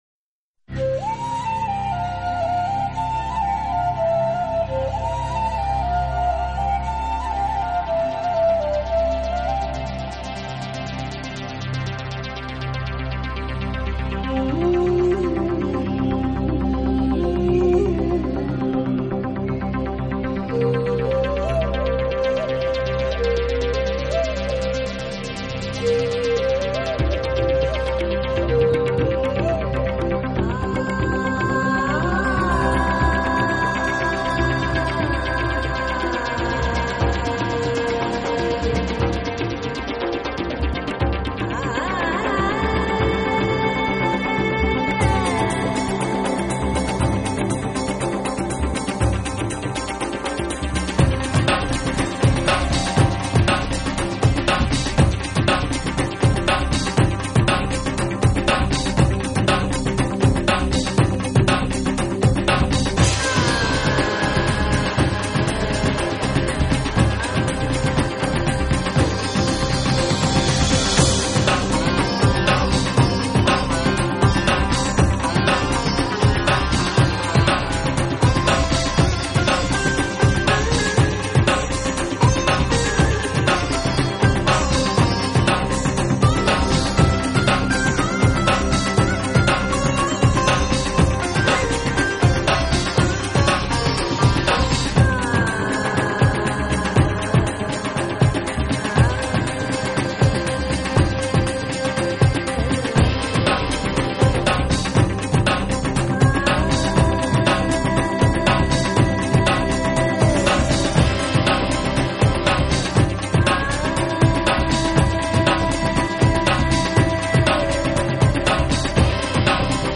【新世纪音乐】
融和了豐富多樣的音樂元素，比如手鼓、朵兒鼓(一種印度大鼓)，
瓦拉曼達拉琴，揚琴山都爾、樂器沙藺吉、長笛、西塔琴、以及現代鍵盤類樂器。